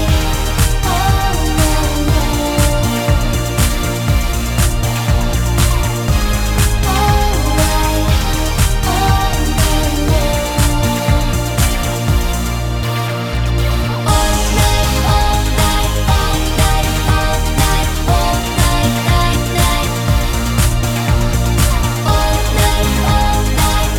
With Rapper Pop (2010s) 3:52 Buy £1.50